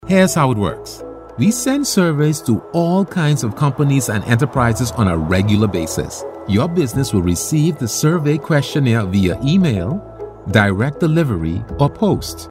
Englisch (Karibik)
Unternehmensvideos
Neumann U87
BaritonBassNiedrig
VertrauenswürdigUnternehmenAutorisierendSamtig